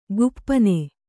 ♪ guppane